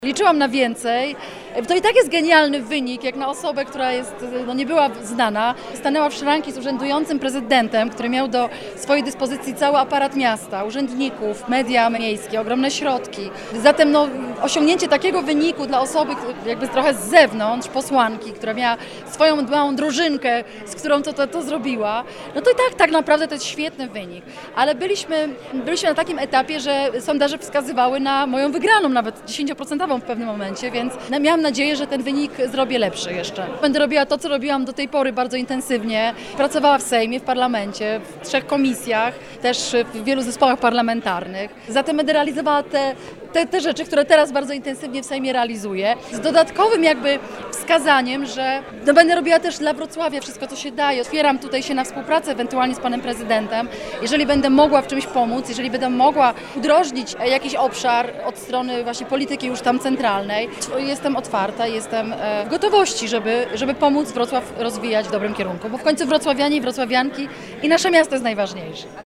Zajrzeliśmy także do sztabu posłanki Izabeli Bodnar.